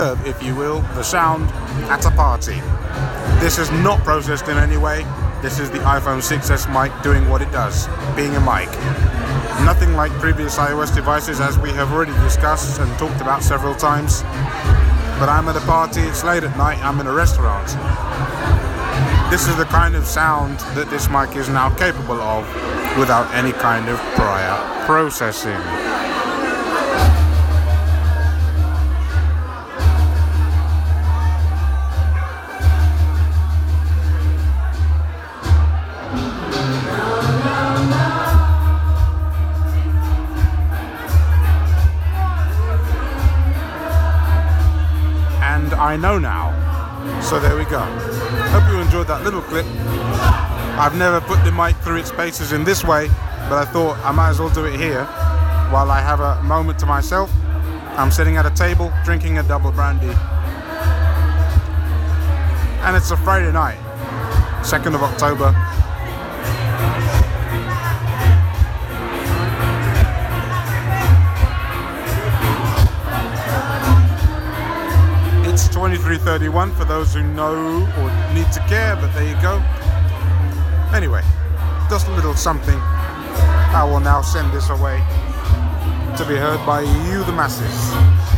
I continue to be wowed by the microphone in this thing.